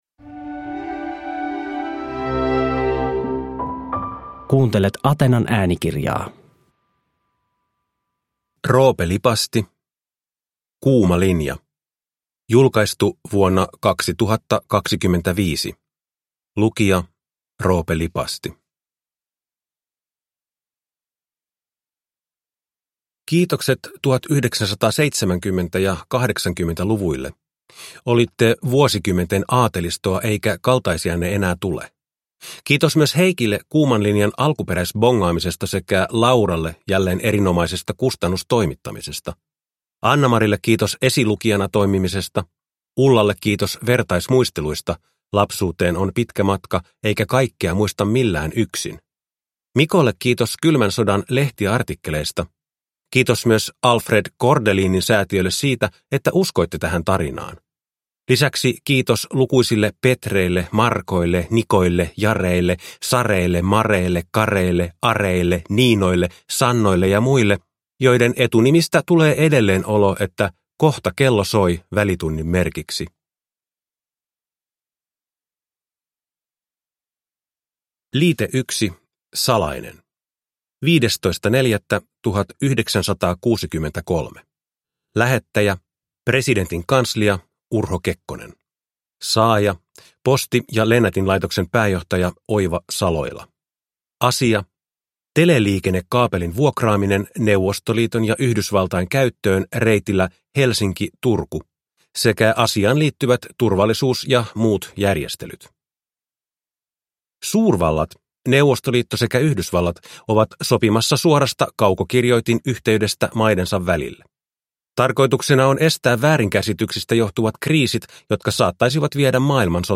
Kuuma linja (ljudbok) av Roope Lipasti